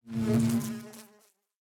Minecraft Version Minecraft Version latest Latest Release | Latest Snapshot latest / assets / minecraft / sounds / block / beehive / work4.ogg Compare With Compare With Latest Release | Latest Snapshot